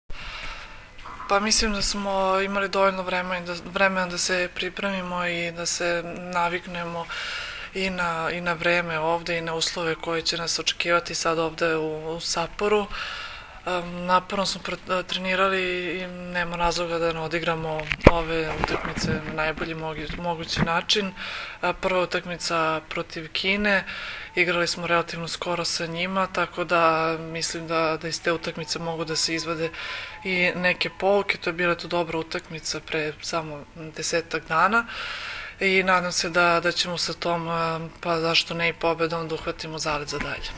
IZJAVA JELENE NIKOLIĆ